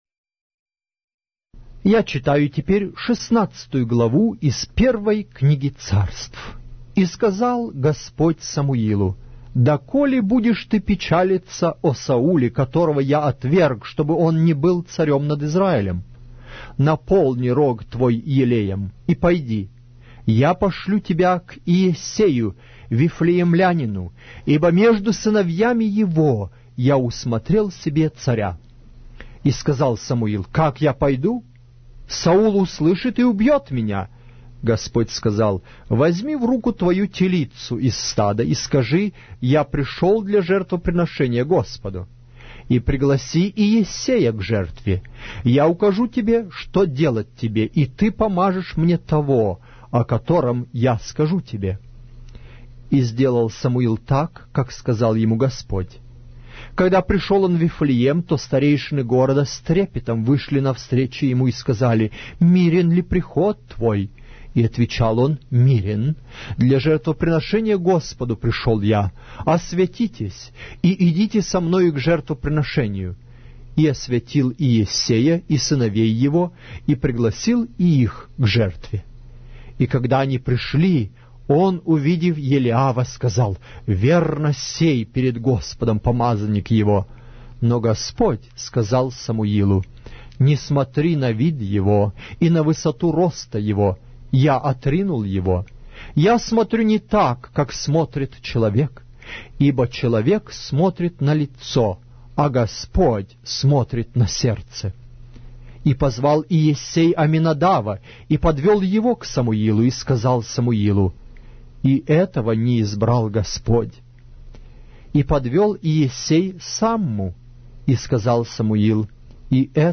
Глава русской Библии с аудио повествования - 1 Samuel, chapter 16 of the Holy Bible in Russian language